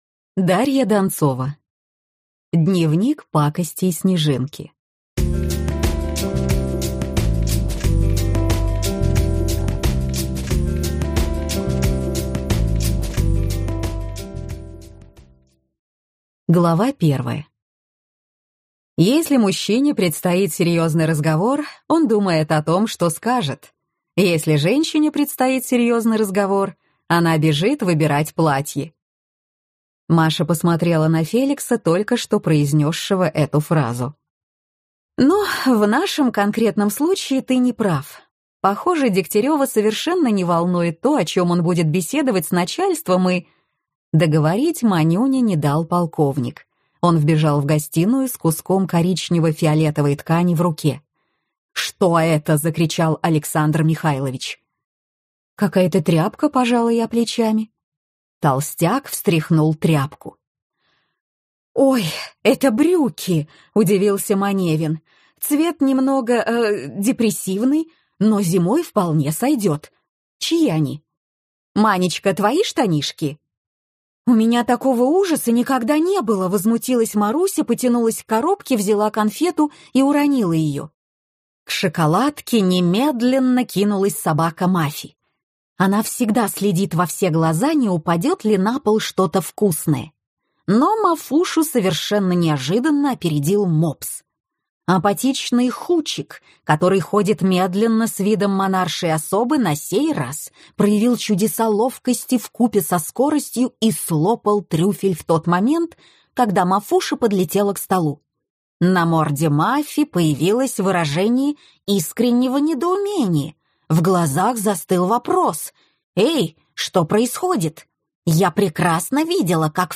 Аудиокнига Дневник пакостей Снежинки - купить, скачать и слушать онлайн | КнигоПоиск